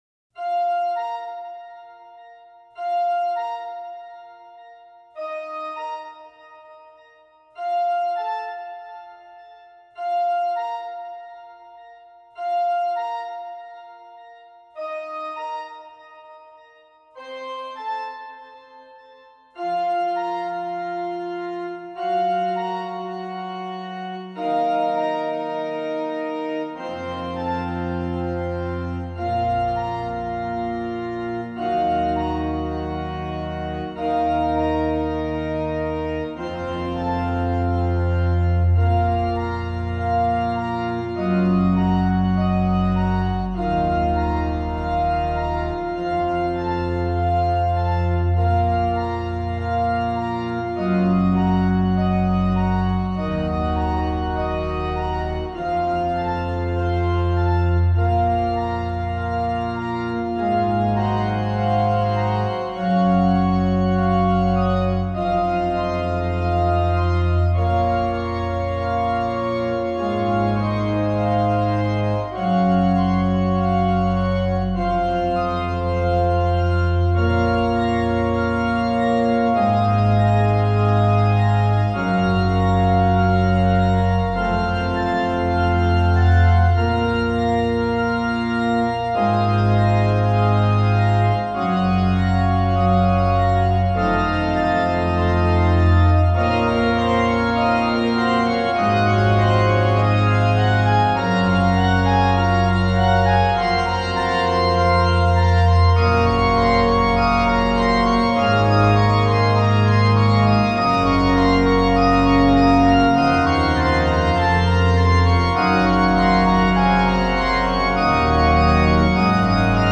METICULOUS ORGAN SAMPLES
FAMILIAR AND FLEXIBLE ORGAN SOUNDS